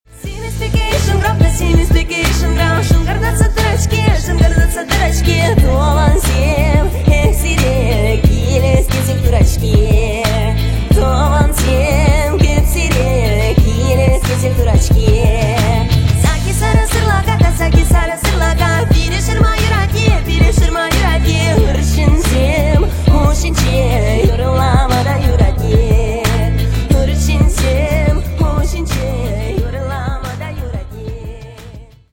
восточные